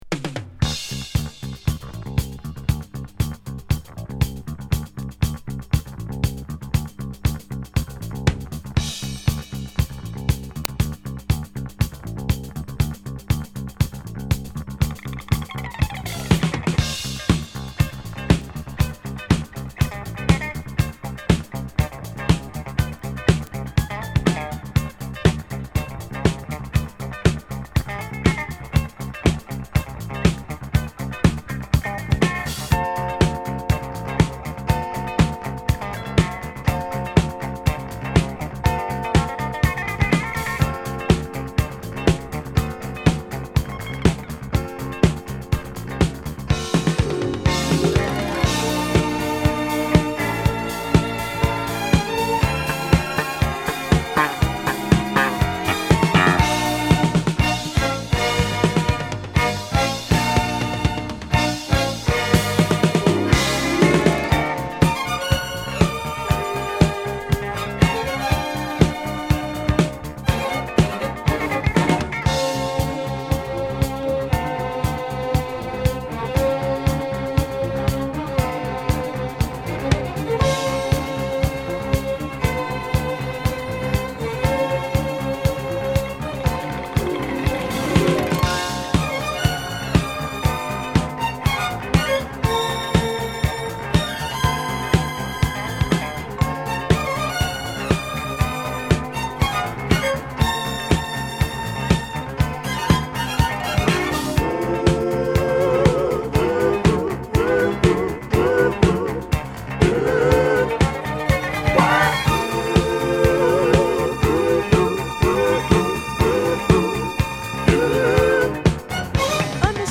洗練されたモダンなソウル／ディスコに仕上げたヒット曲！